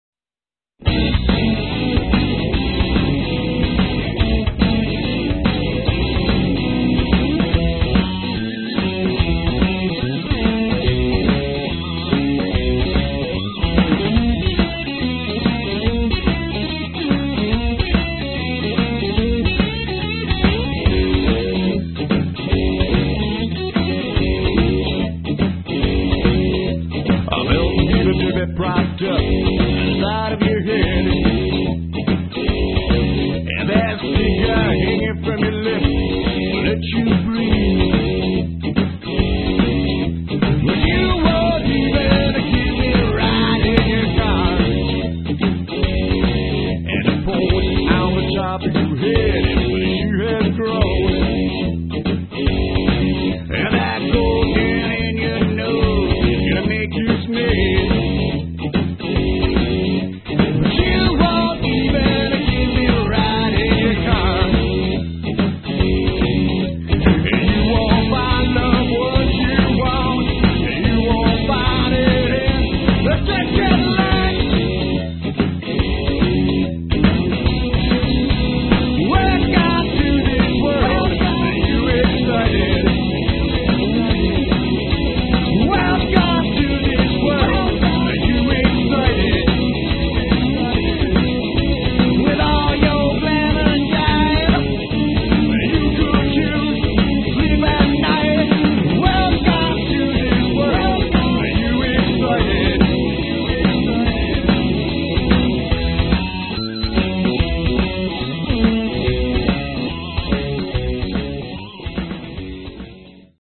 Style: Blues Rock